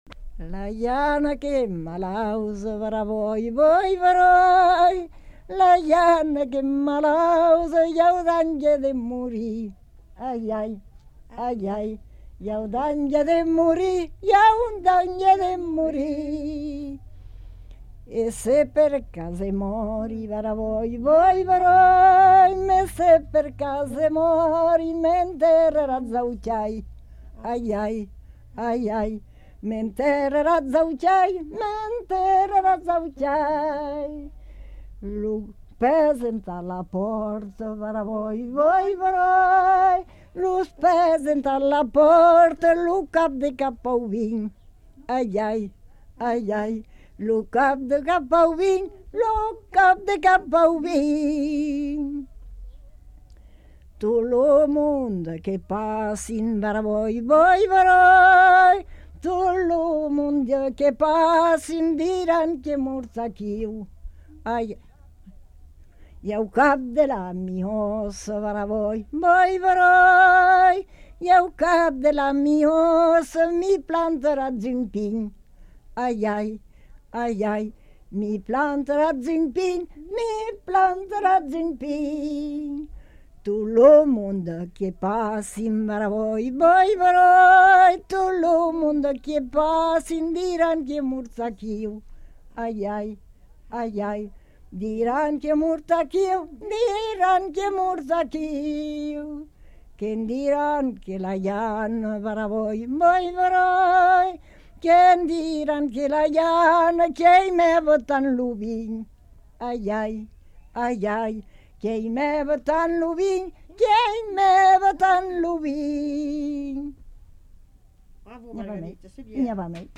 Lieu : Mont-de-Marsan
Genre : chant
Type de voix : voix de femme
Production du son : chanté